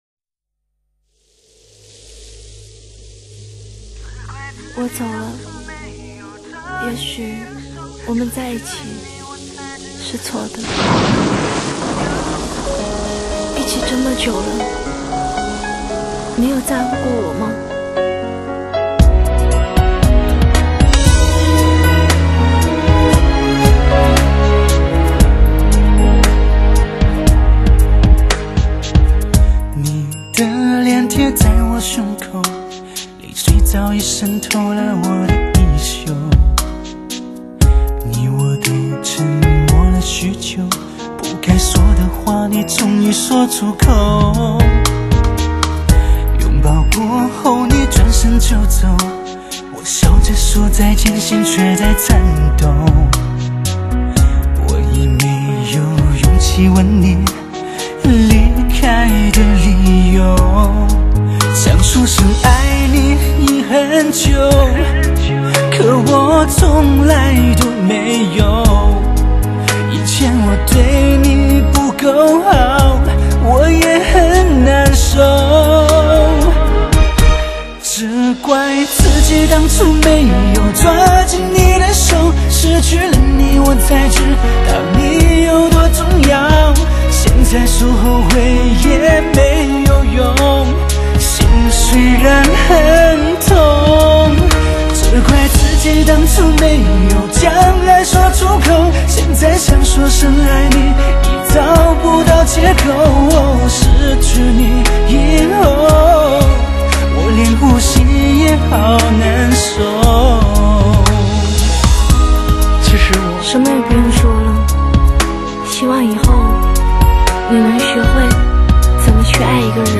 国际音响协会多声道音乐录音典范，1:1德国母盘直刻技术，
塑造无比传真的高临场音效，极富视听效果的发烧靓声，
低沉而独特的磁性嗓音，不失深情地浅唱轻吟沁入你的心底，